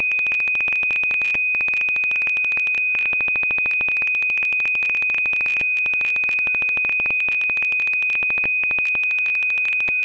耳机接收基本音频数据、但它有一些缺失/重复的值。
大约每75ms 重复大约8个值(因此有某种模式、但不一致)。
频率为2.5kHz 的音频采样、直接从 CC2564的 PCM 输出中记录。